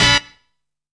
PIC TRUMPHIT.wav